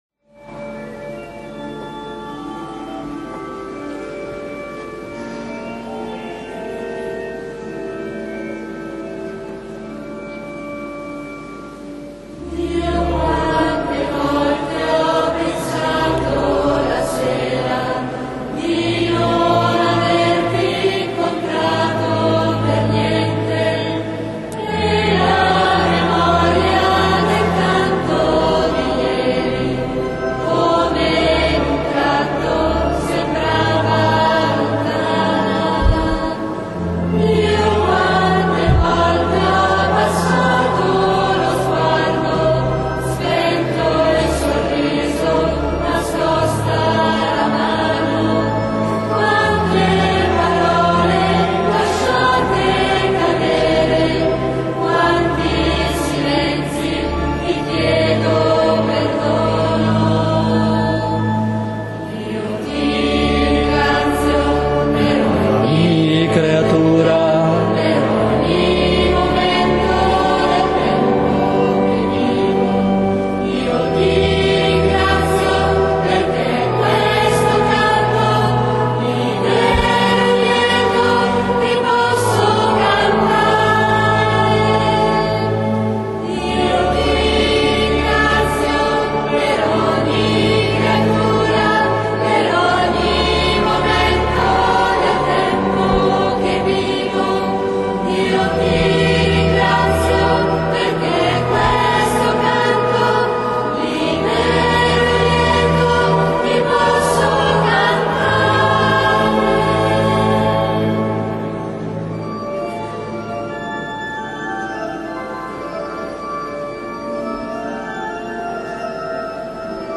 XXIV sabato ordinario (Unzione degli infermi tenerezza di Dio)
canto: Ora che il giorno finisce